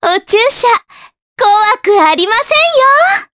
Mediana-Vox_Skill5_jp.wav